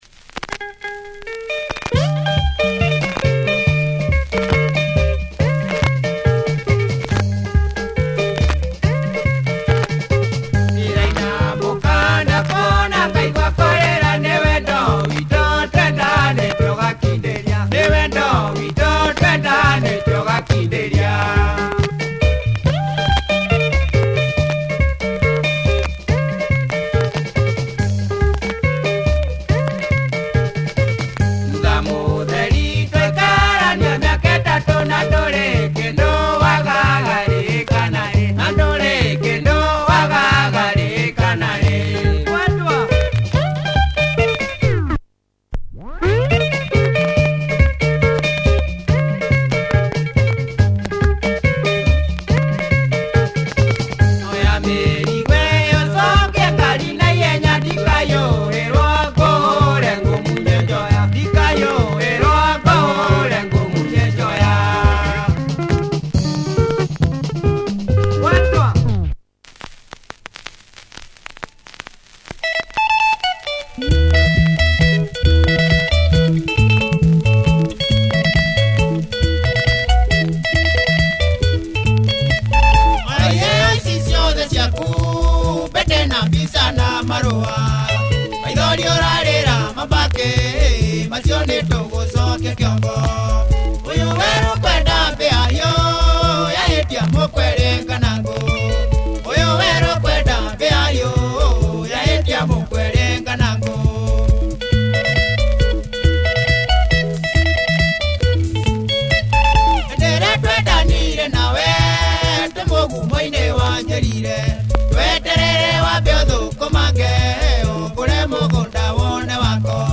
Distinct Benga